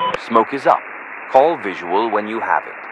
Radio-jtacSmokeOK5.ogg